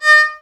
VIOL 3 D#4-L.wav